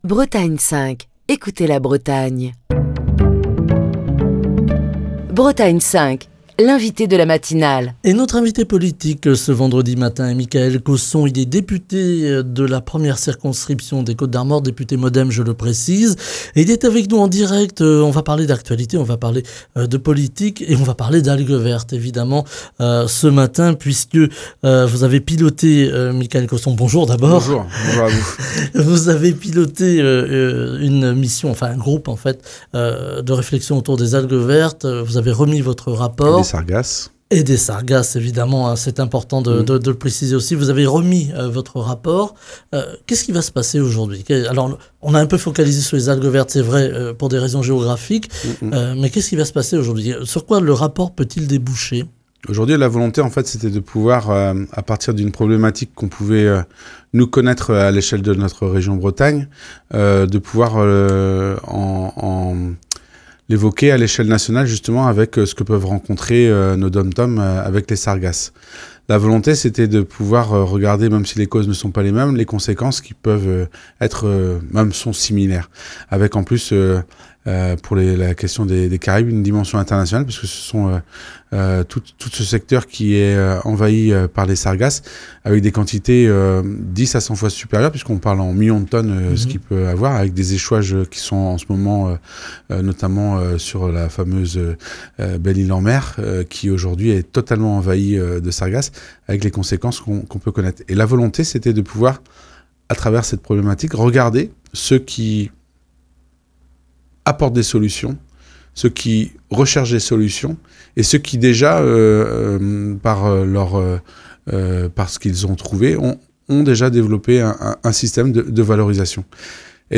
Mickaël Cosson, député MoDem de la 1ère circonscription des Côtes-d'Armor était l'invité politique de la matinale de Bretagne 5, ce vendredi.